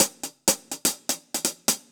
Index of /musicradar/ultimate-hihat-samples/125bpm
UHH_AcoustiHatB_125-01.wav